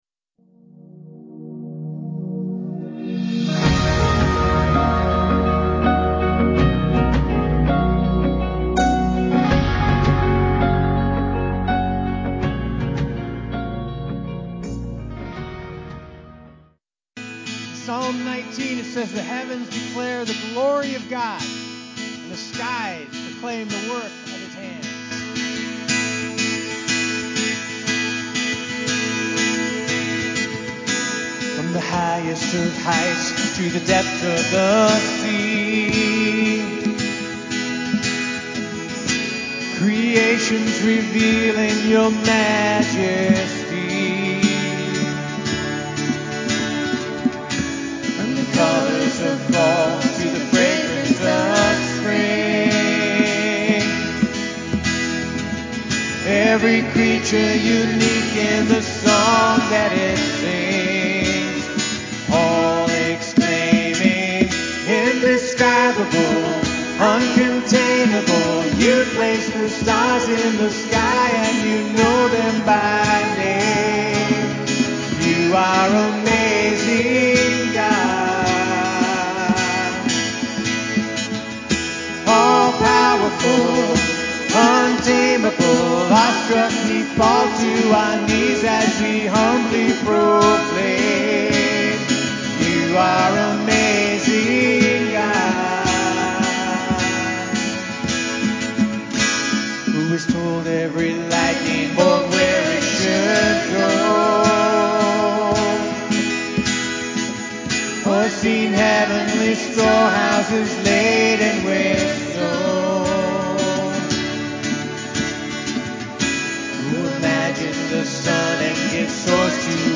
Sermon on 1 John 1 about walking in the light through honesty, confession, and fellowship, featuring a pastor’s personal story of brokenness and spiritual growth.